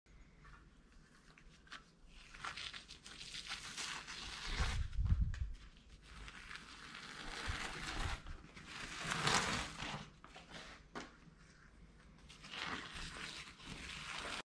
ЗВУКИ РЕМОНТА, ОБОИ
• Категория: Ремонтные работы
• Качество: Высокое